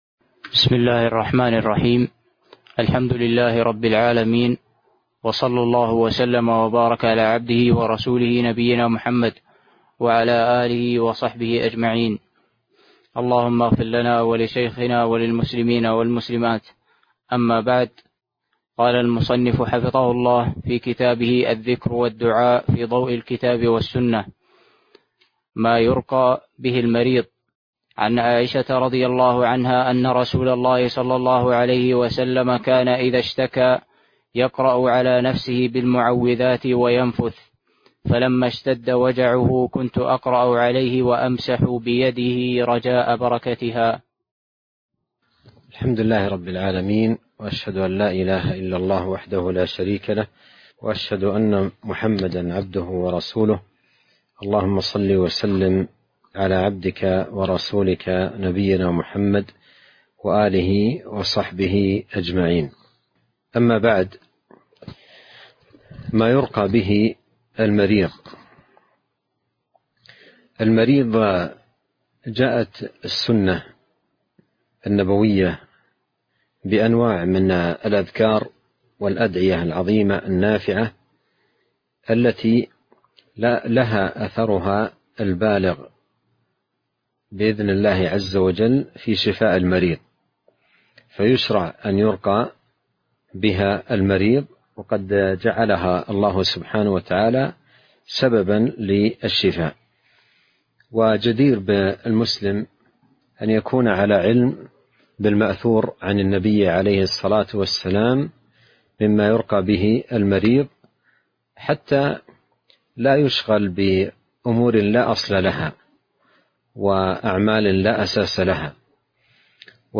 التعليق على كتاب الذكر والدعاء 29 - ما يرقى به المريض